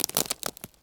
wood_tree_branch_move_14.wav